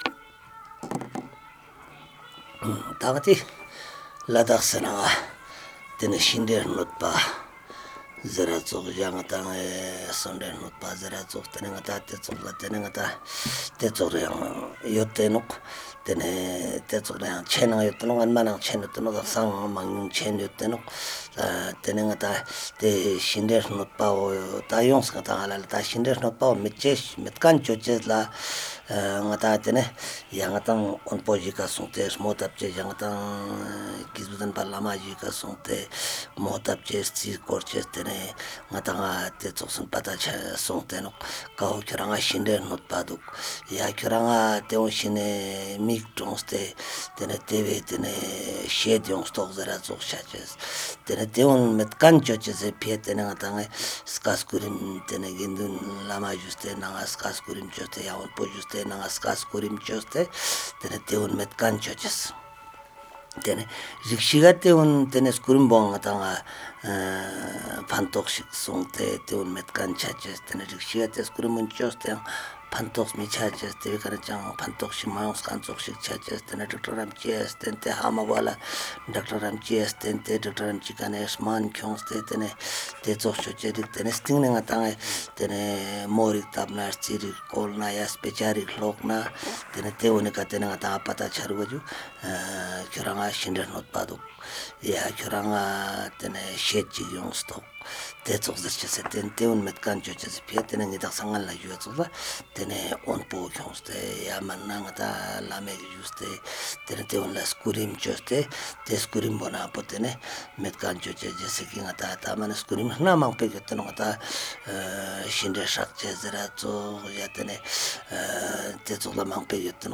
The retelling of a story in Beda - Part 6